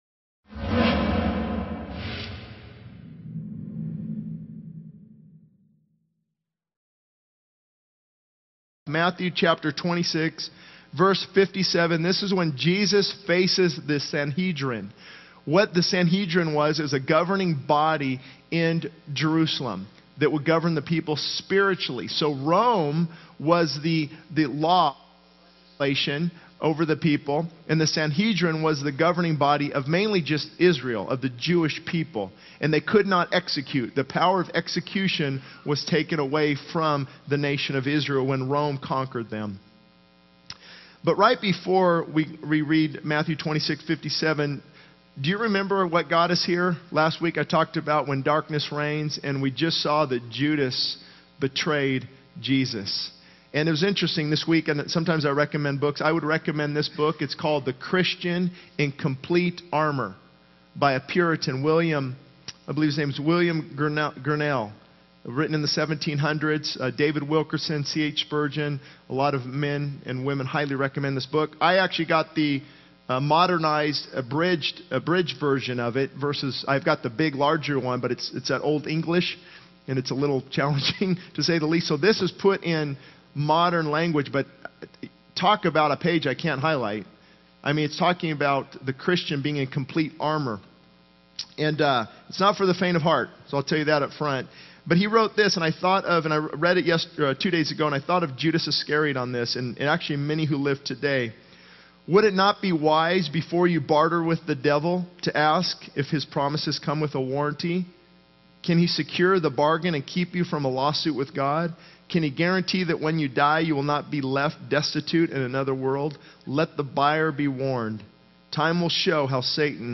This sermon delves into the story of Jesus facing the Sanhedrin, highlighting the significance of surrendering to God and the consequences of denying Him. It emphasizes the importance of repentance, acknowledging one's mistakes, and seeking forgiveness to experience restoration and victory in the Christian walk.